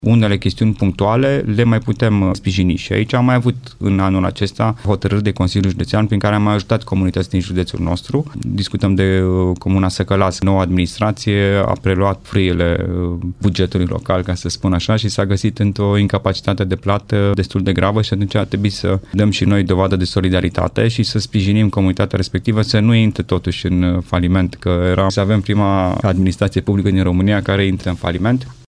Alexandru Iovescu spune că este nevoie de sprijin guvernamental, pentru că bugetul județului nu poate acoperi toate comunitățile aflate în dificultate.